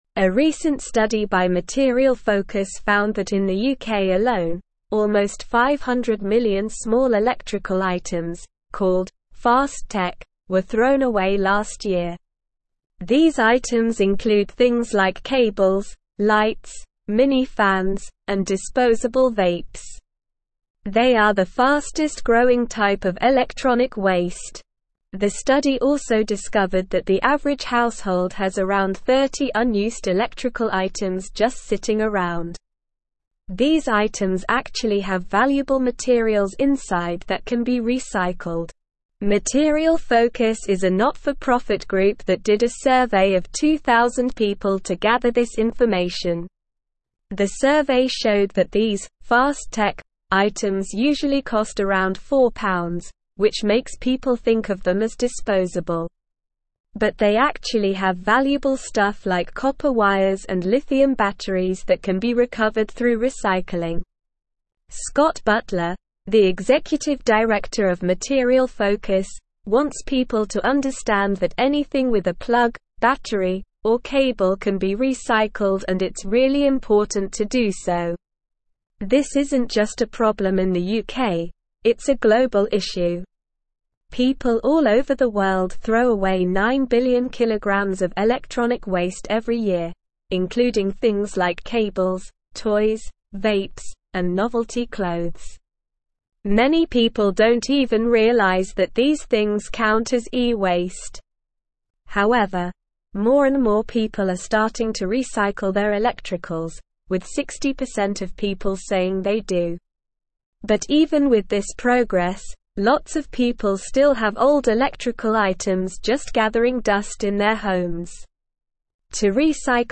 Slow
English-Newsroom-Upper-Intermediate-SLOW-Reading-UK-Urged-to-Recycle-Fast-Tech-E-Waste.mp3